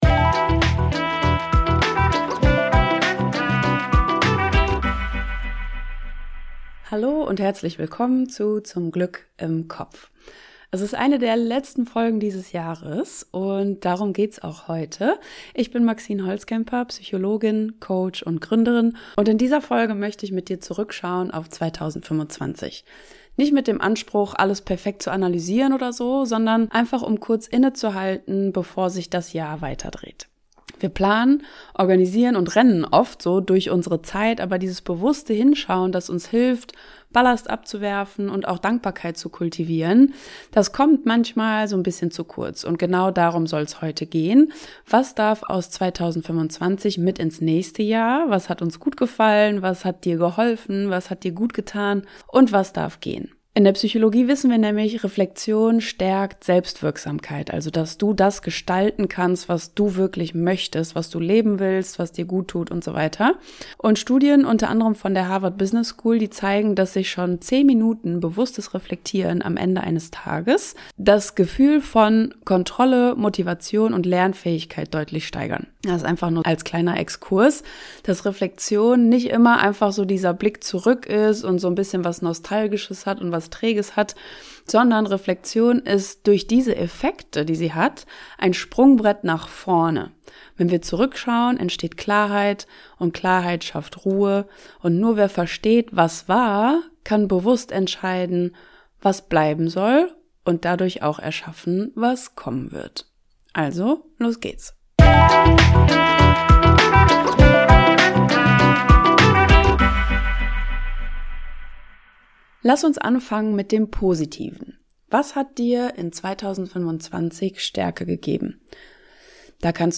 In dieser kurzen, kraftvollen Solo-Folge unterstütze ich dich dabei, dein Jahr 2025 intuitiv zu reflektieren.